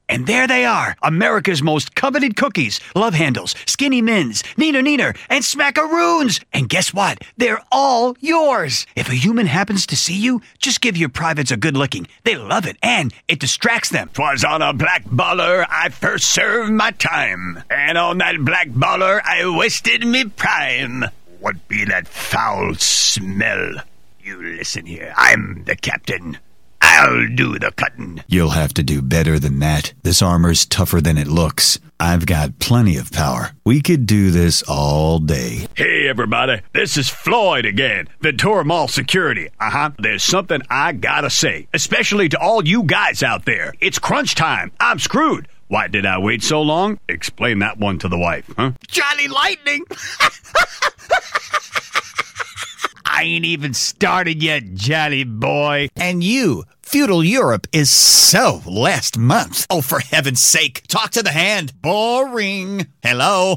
Animation VO Demo